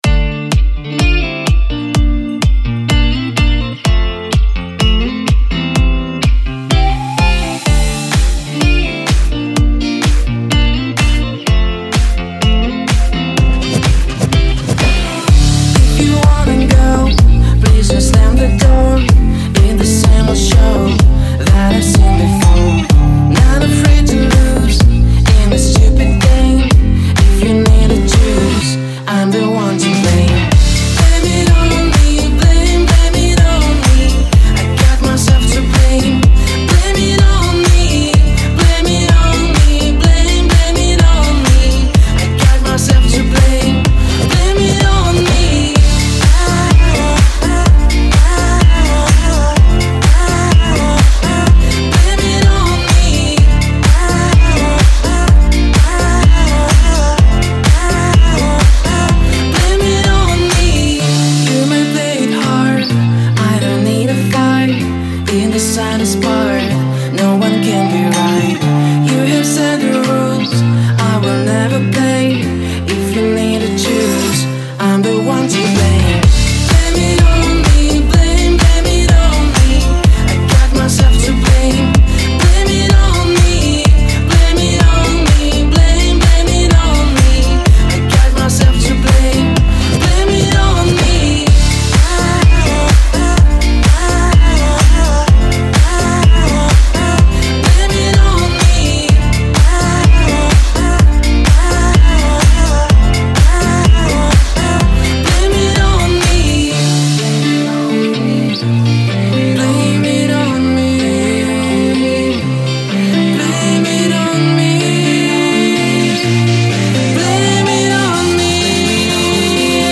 это яркий трек в жанре электронная музыка